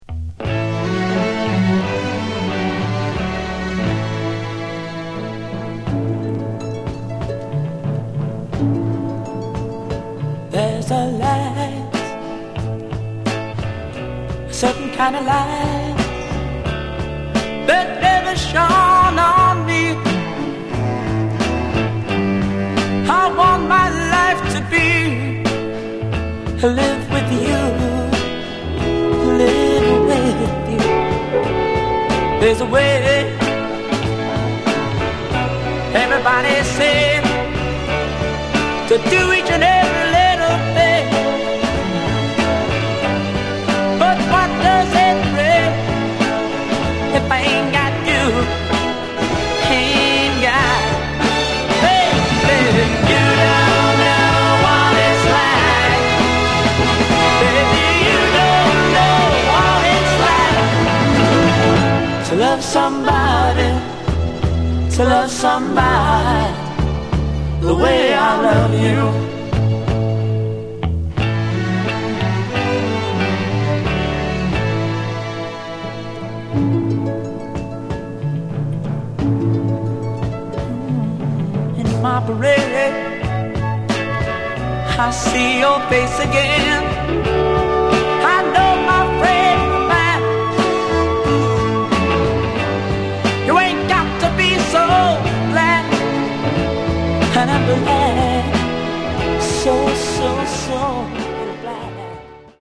Genre: Power Pop
and it's got a great arrangement going, too.